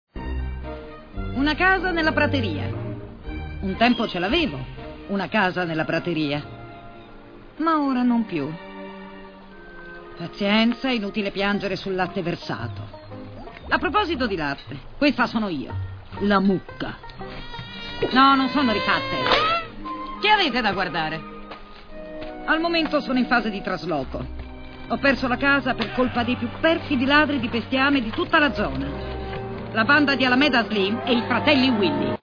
voce di Cinzia Leone dal film d'animazione "Mucche alla riscossa", in cui doppia Maggie.